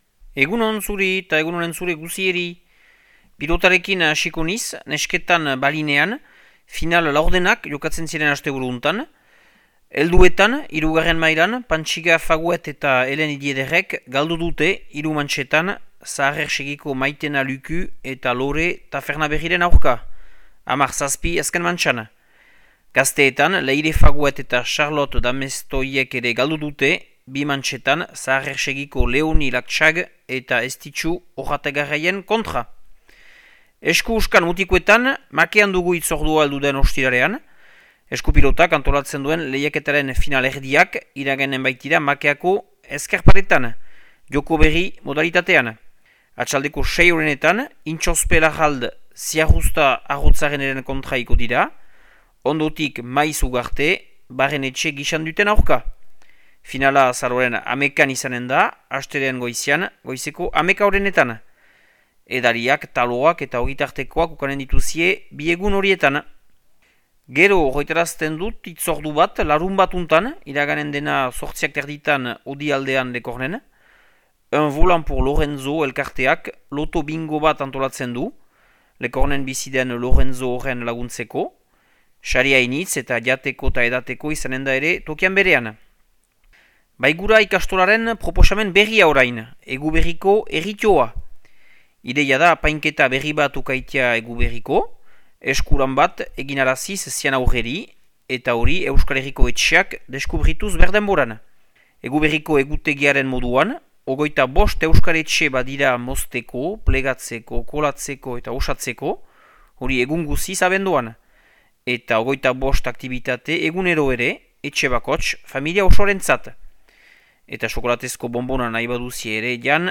Azaroaren 4ko Makea eta Lekorneko berriak